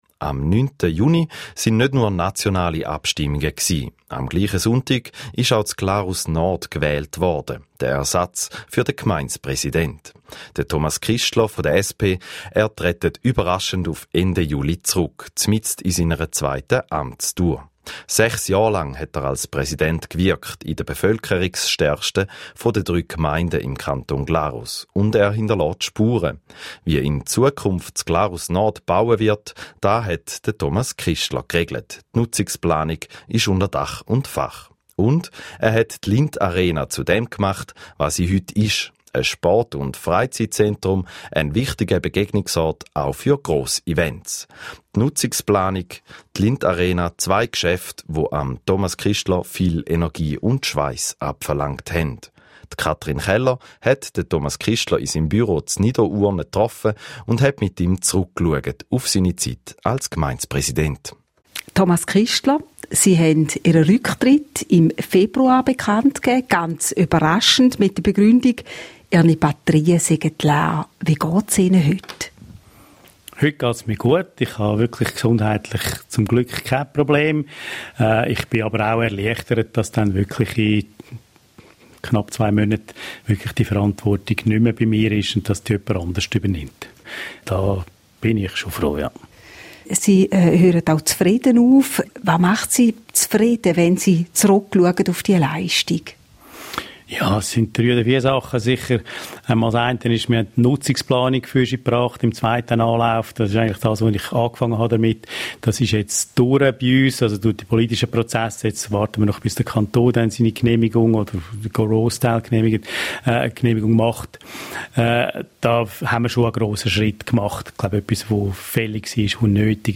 Anfangs Juli veröffentlichte die Zeitung Südostschweiz eine Würdigung dazu ( Link ) und noch im Juni führte das Regionaljournal srf Ostschweiz ein Interview mit mir ( Link ).